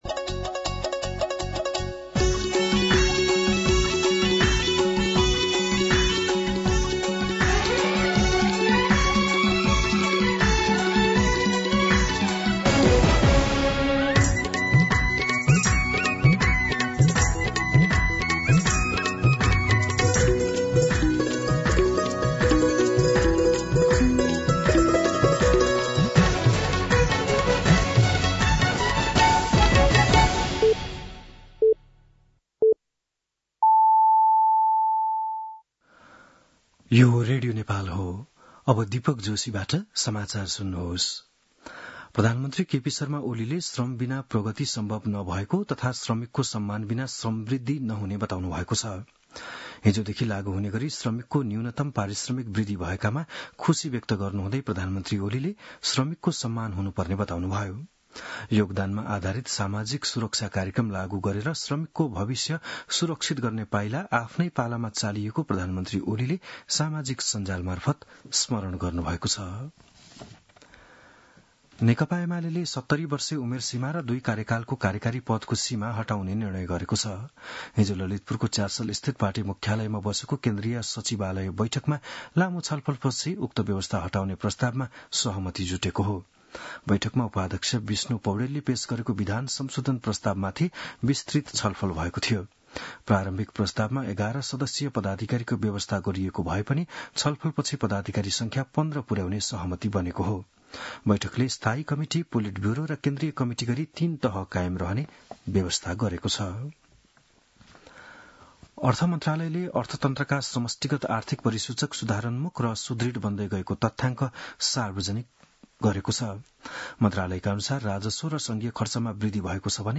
An online outlet of Nepal's national radio broadcaster
बिहान ११ बजेको नेपाली समाचार : २ साउन , २०८२